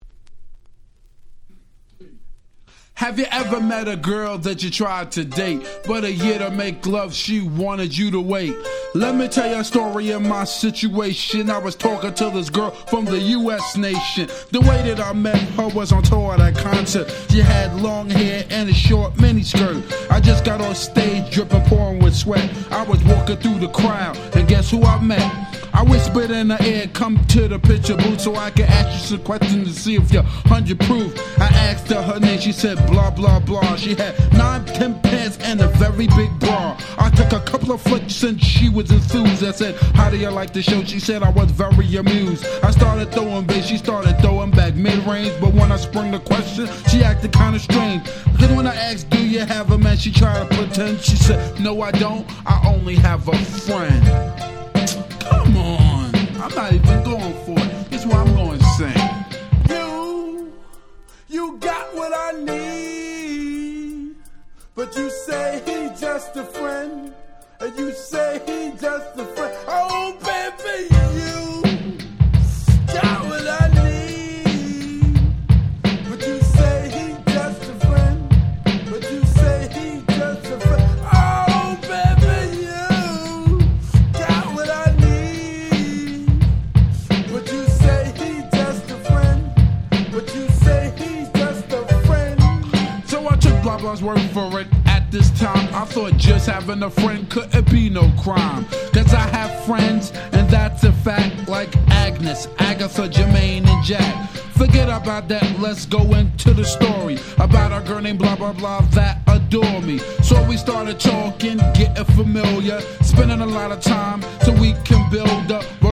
89' Super Hip Hop Classics !!
相変わらずのヘタっぴな歌、でも何故かめちゃめちゃ哀愁が漂います。